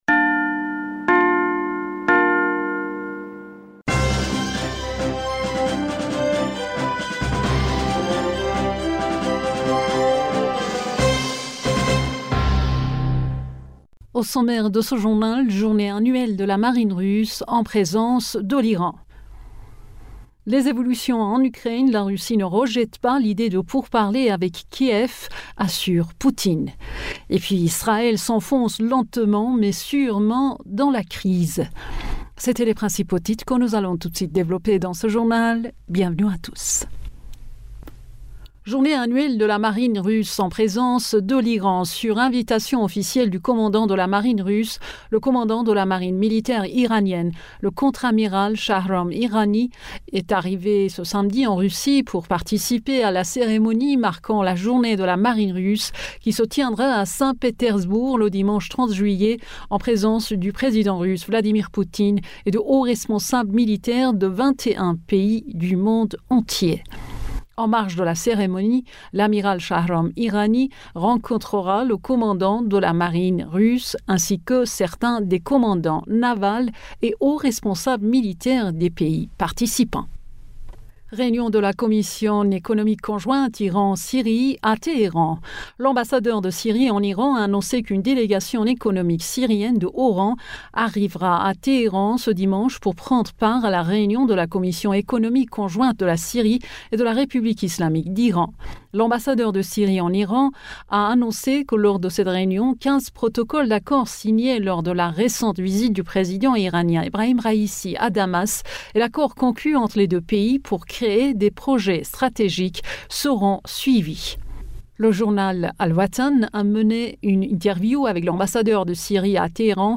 Bulletin d'information du 30 Juillet 2023